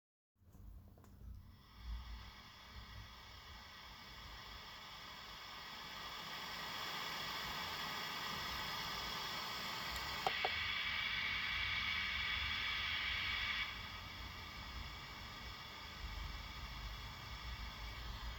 Marshall MG15R - Rauschen mit und ohne angeschl. Gitarre
Habe erst auf Clean von unten hochgedreht und dann auf Overdrive umgeschaltet und Gain von 100 auf 0 runtergedreht